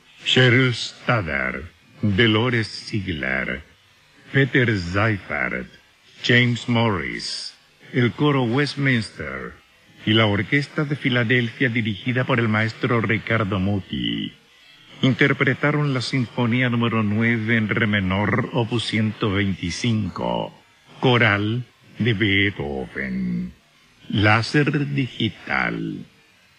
Sin embargo, el tiempo fraguó un cariño auténtico y libre de prejuicios por su forma tan especial de hablar. Ese fraseo lento, ceremonioso y con inflexiones quebradas de: “Galletas McKay más ricas no hay” me acompañará por siempre.